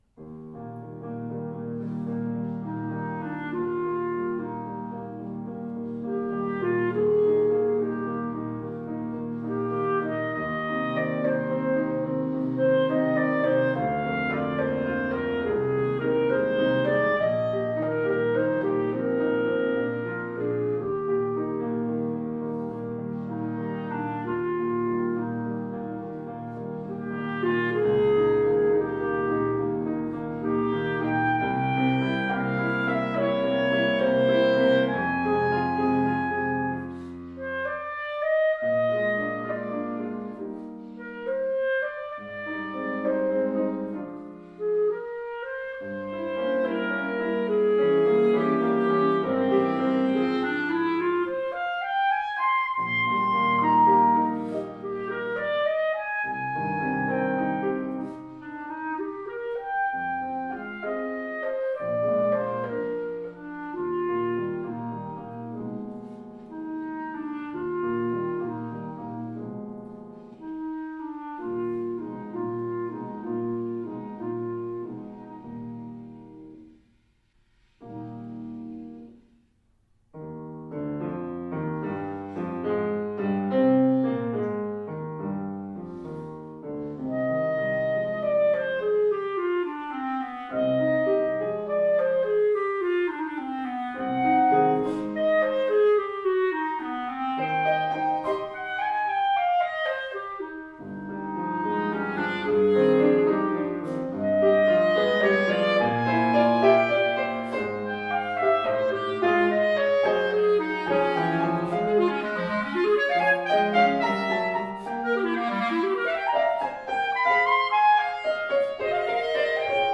piano
Style: Classical
clarinet
clarinet-sonata-op-167.mp3